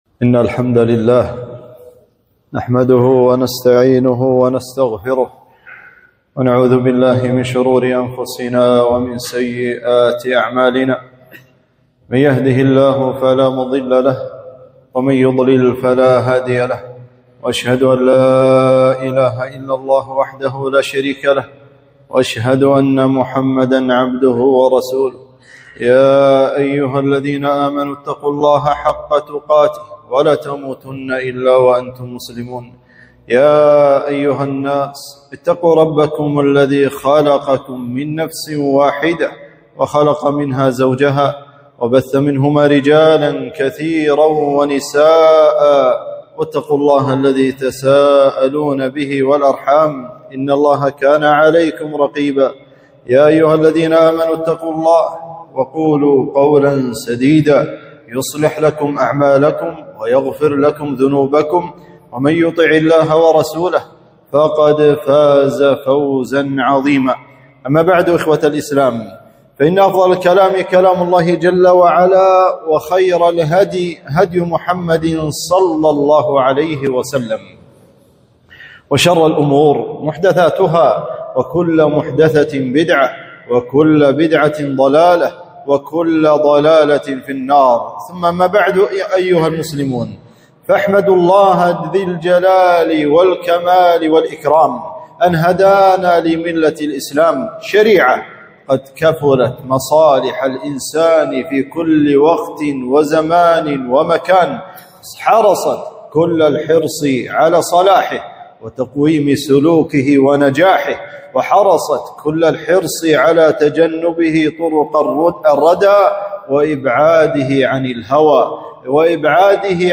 خطبة - حق الجار